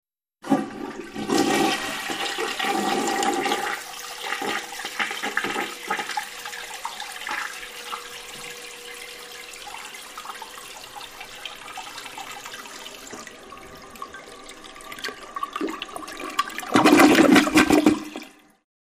Toilet Flush 3; Typical Flush Routine; Handle Is Pressed, Water Then Flows Abruptly With Water Gurgles. Close Perspective. Bathroom.